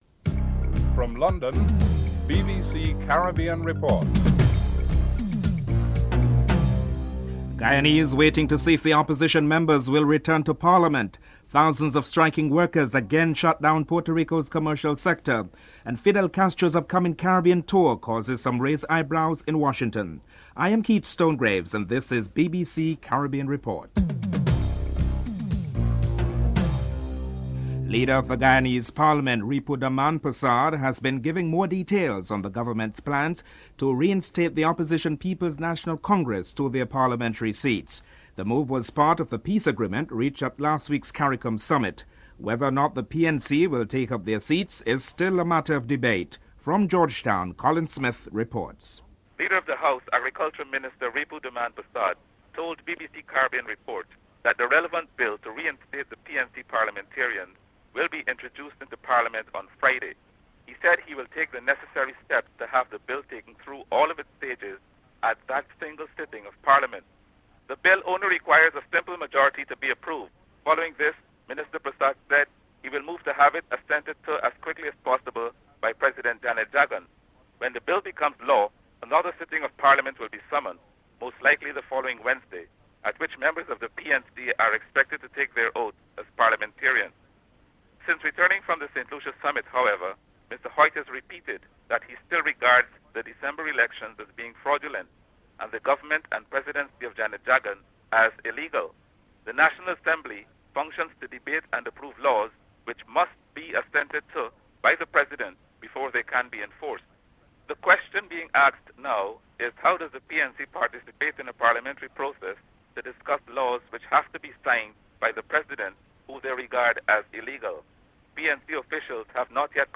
Attorney General David Simmons is interviewed (13:21-15:25)